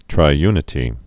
(trī-ynĭ-tē)